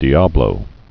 (dēblō)